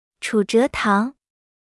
Pronunciation 👀
pronunciation.mp3